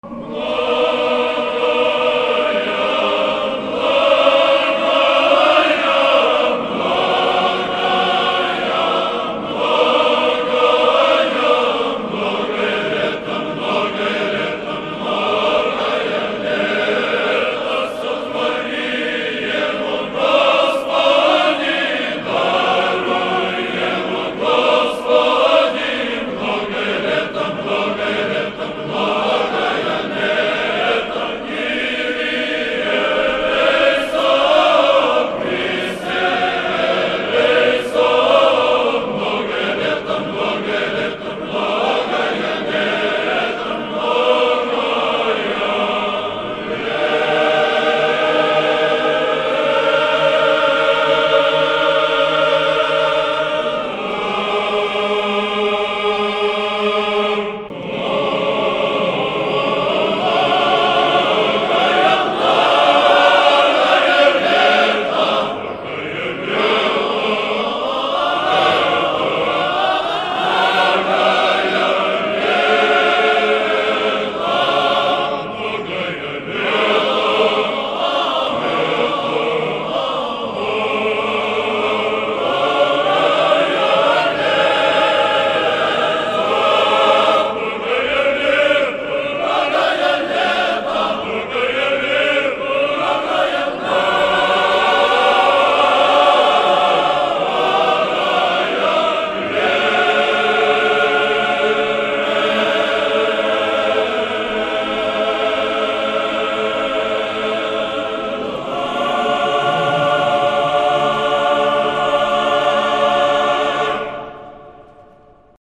Хор Троице - Сергиевой Лавры МНОГАЯ ЛЕТА Многая лета Вам на Земле МНОГАЯ ЛЕТА По окончании праздничной Божественной Литургии духовенство и прихожане храма во имя прп.
Хор-Троице-Сергиевой-Лавры-МНОГАЯ-ЛЕТА.mp3